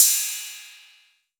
808CY_6_Orig.wav